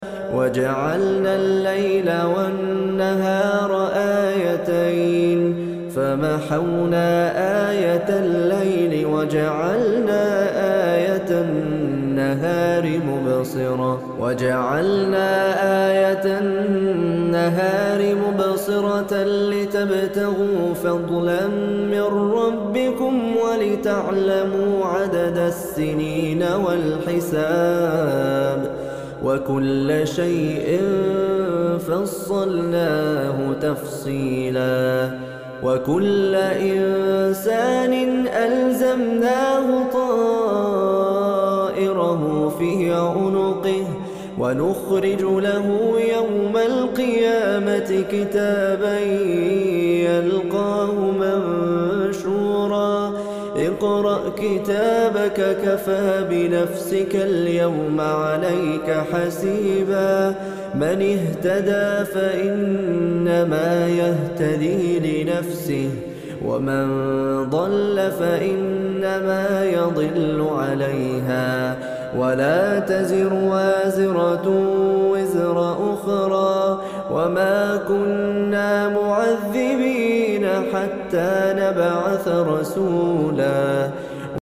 احسن تلاوة صوت عدب